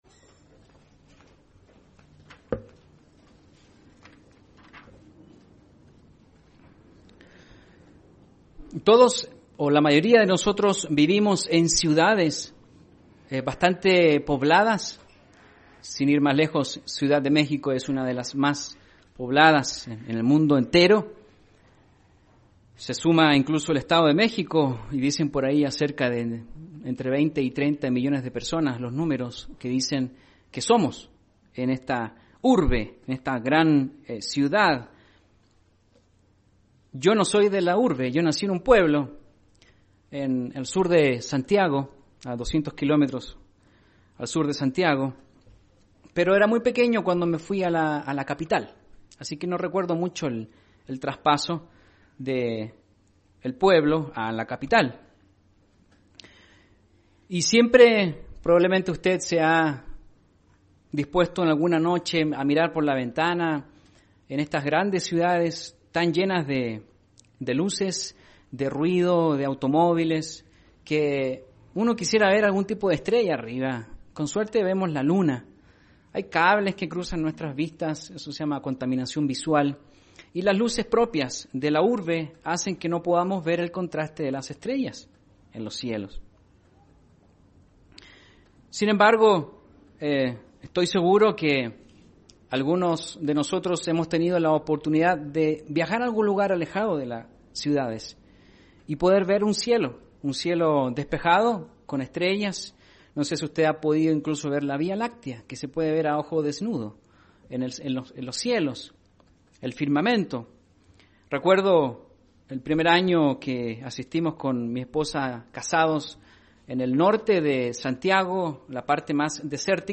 La inquebrantable fe de personajes como Abraham, es un ejemplo de que ésta nos acerca a la Justicia de Dios y a su presencia. Mensaje entregado el 14 de Julio de 2018.